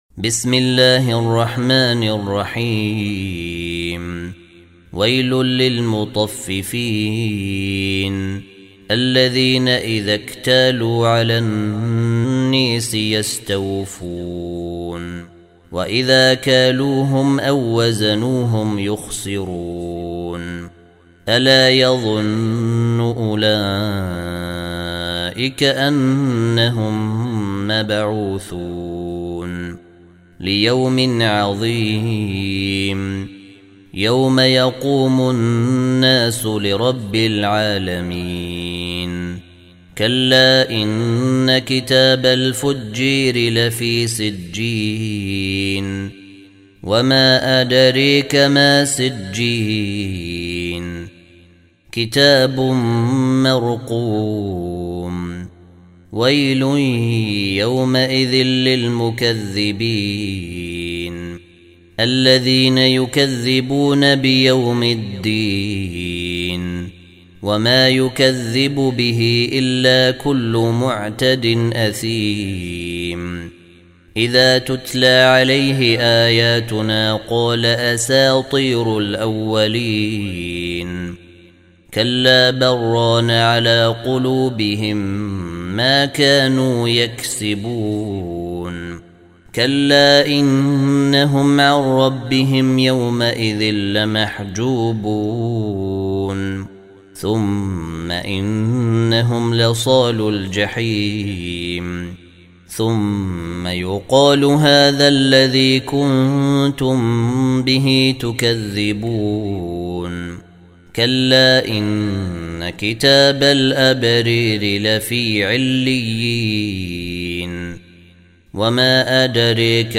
83. Surah Al-Mutaffif�n سورة المطفّفين Audio Quran Tarteel Recitation
Surah Repeating تكرار السورة Download Surah حمّل السورة Reciting Murattalah Audio for 83.